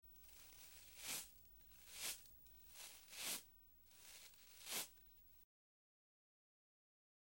Звуки расчёски
Человек расчесывает волосы